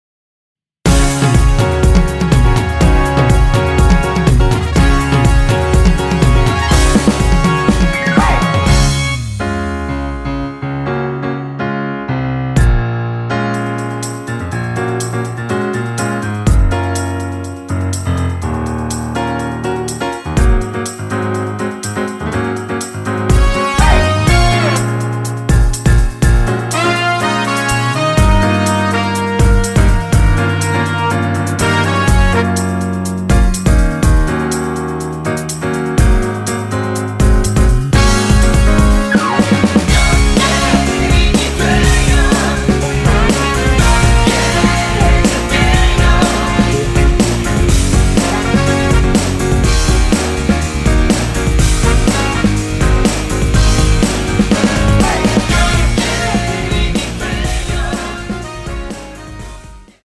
원키에서(-1) 내린 코러스 포함된 MR 입니다.(미리듣기 참조)
Db
앞부분30초, 뒷부분30초씩 편집해서 올려 드리고 있습니다.
중간에 음이 끈어지고 다시 나오는 이유는